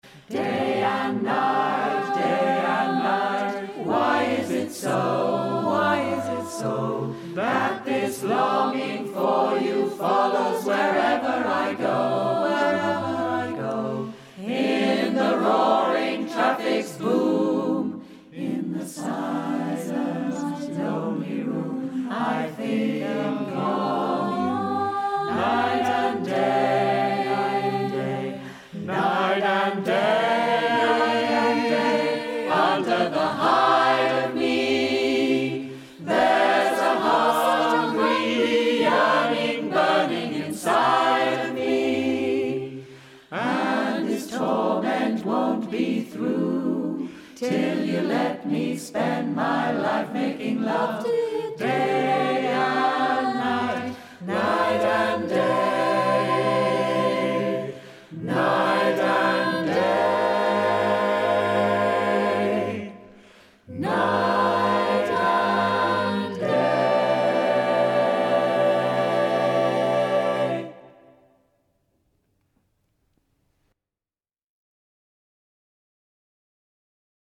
calm and riotous, exultant and tranquil